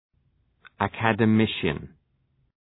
Shkrimi fonetik{,ækədə’mıʃən, ə,kædə’mıʃən}